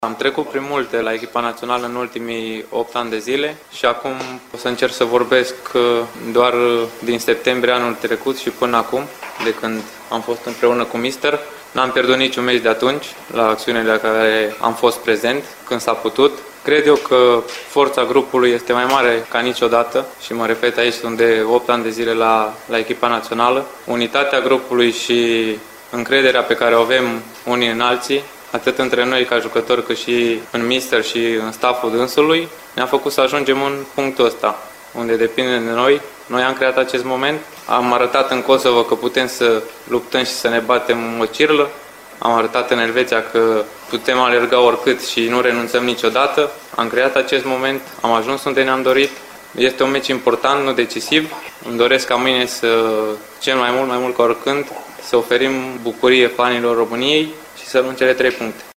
Despre atmosfera bună de la lot a vorbit Nicolae Stanciu:
Stanciu-forta-grupului.mp3